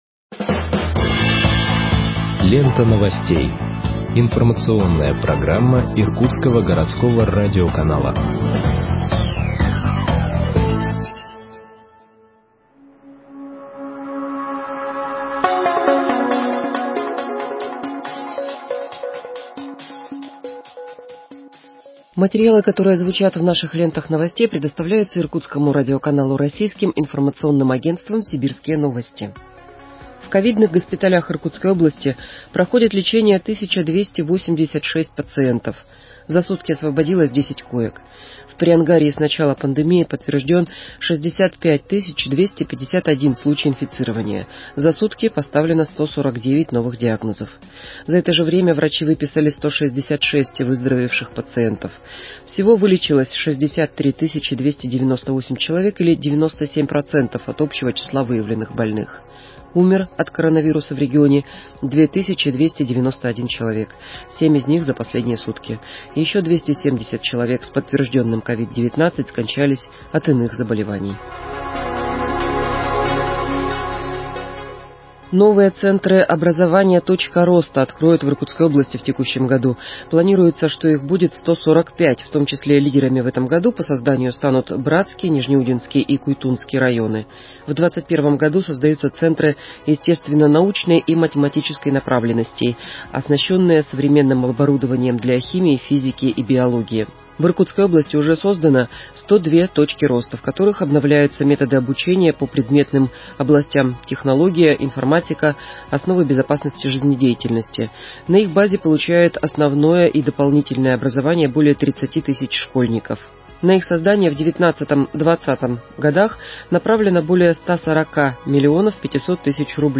Выпуск новостей в подкастах газеты Иркутск от 23.04.2021 № 2